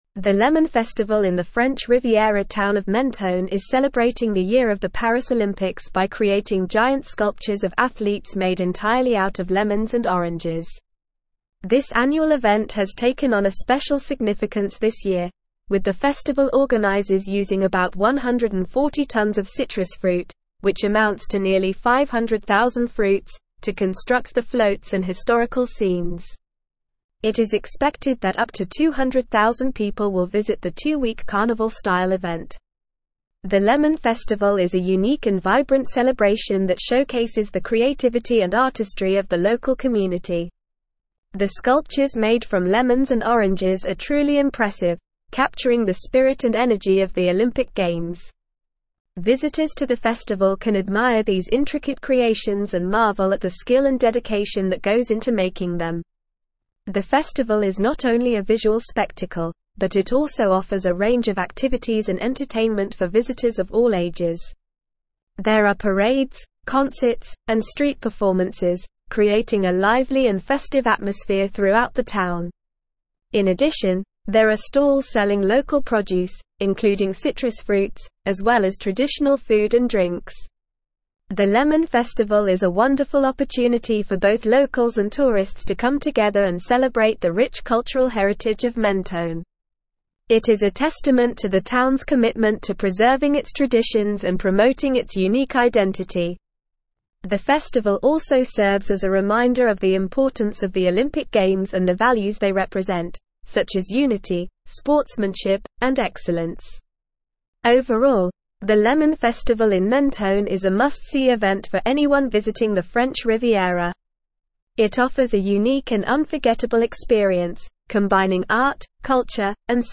Normal
English-Newsroom-Advanced-NORMAL-Reading-Lemon-Festival-in-Menton-Celebrates-Paris-Olympics.mp3